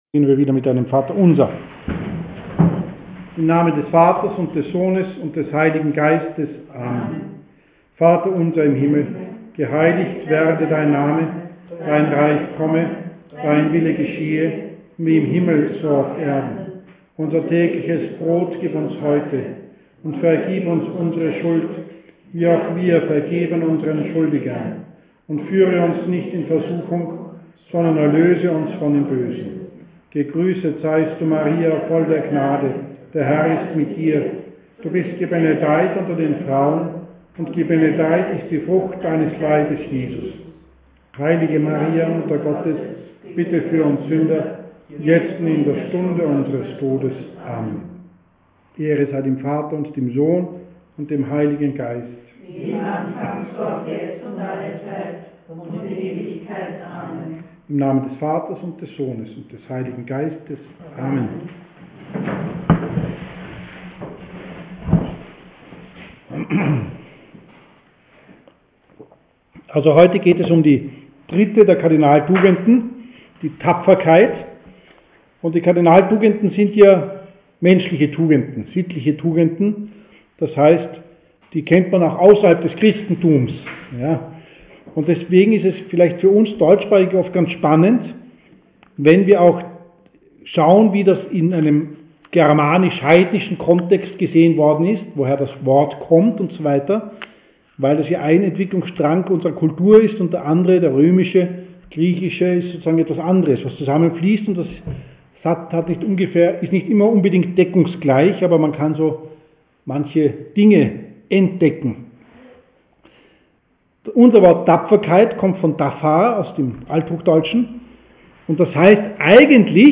Katechesen über die Tugenden zum Nachhören